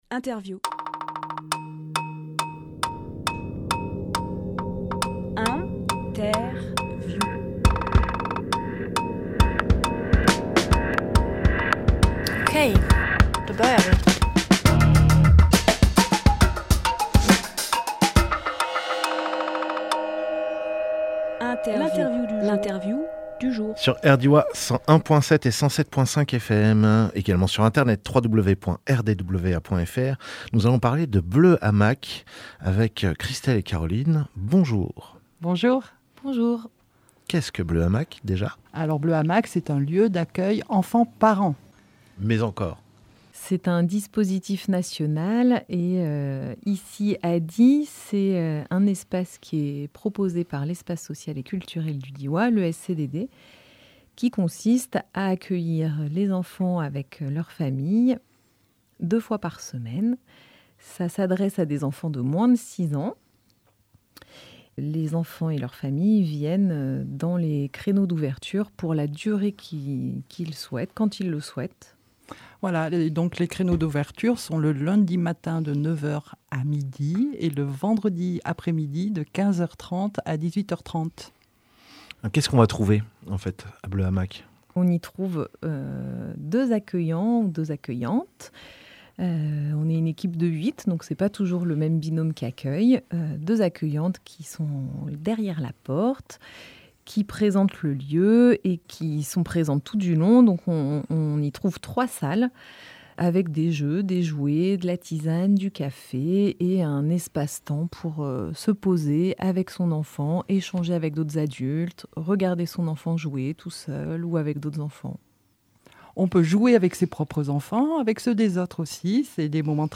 Emission - Interview Bleu Hamac Publié le 5 octobre 2023 Partager sur…
Lieu : Studio RDWA